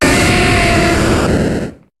Cri de Typhlosion dans Pokémon HOME.